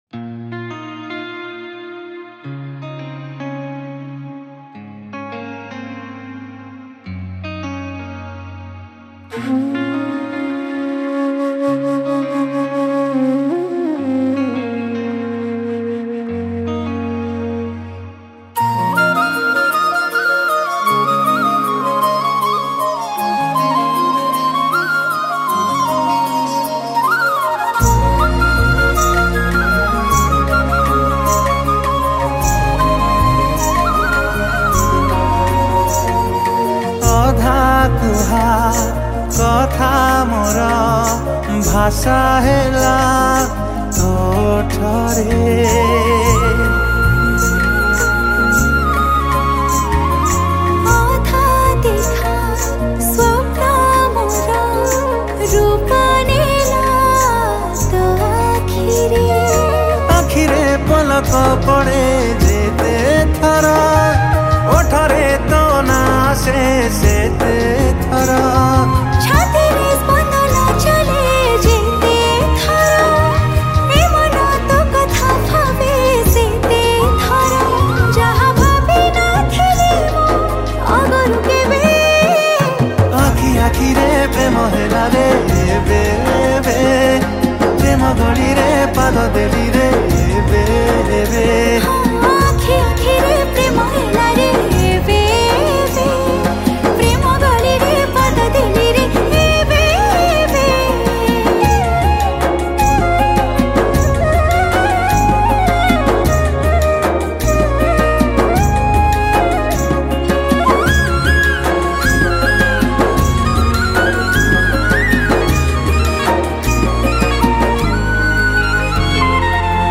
Flute
Percussion